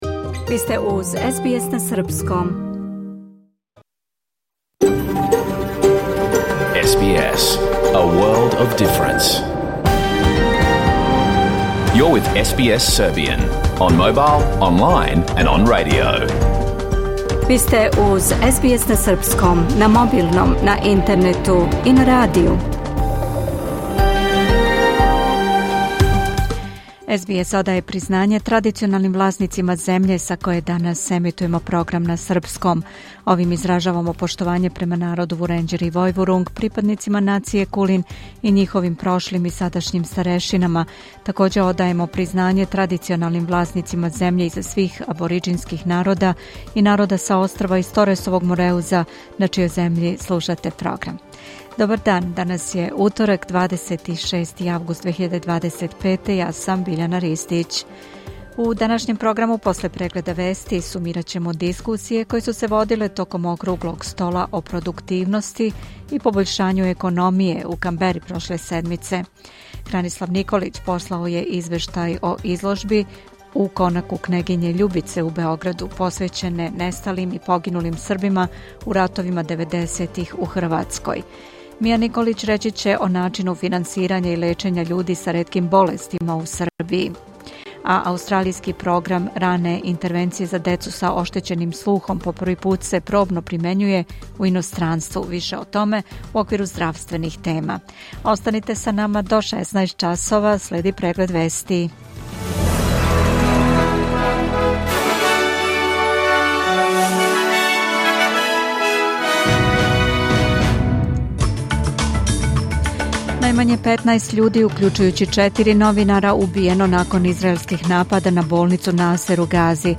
Програм емитован уживо 26. августа 2025. године